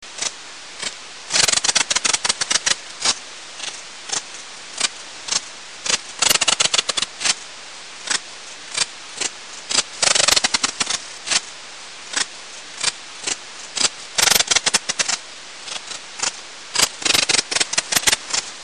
pholidoptera_griseoaptera.mp3